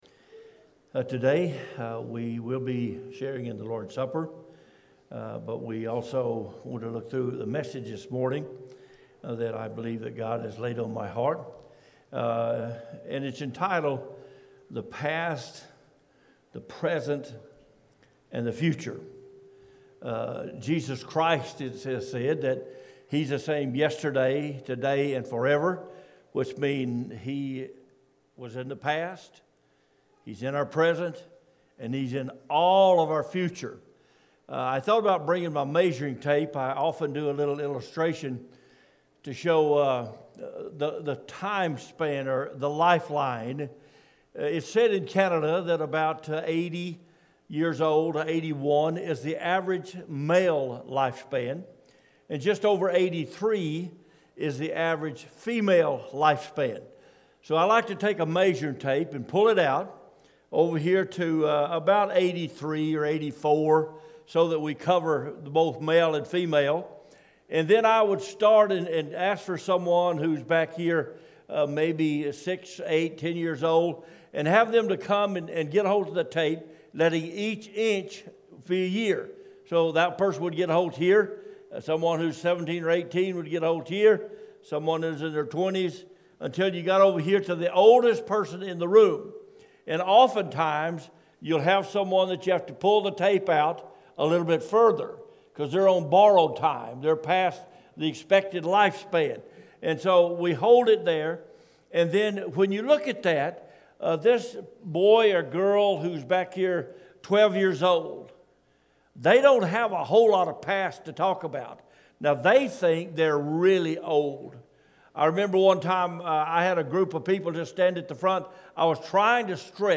Aug-30-sermon-only-audio.mp3